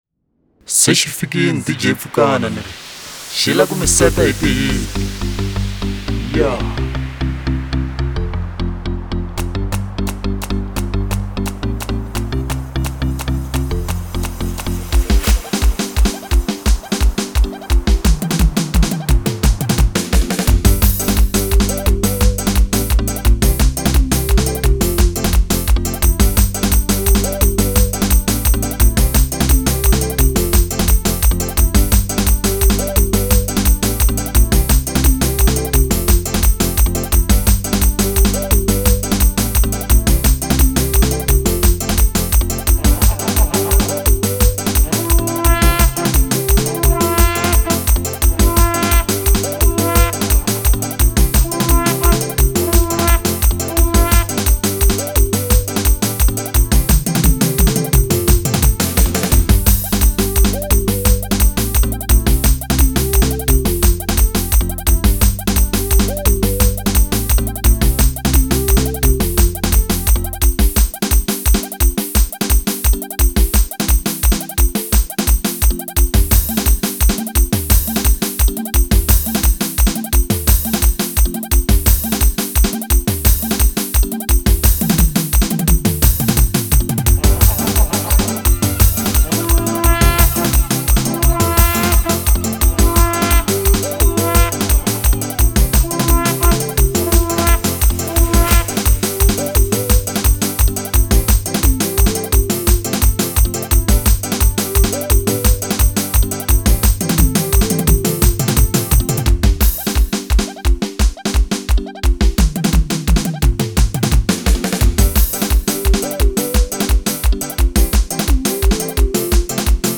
03:57 Genre : Xitsonga Size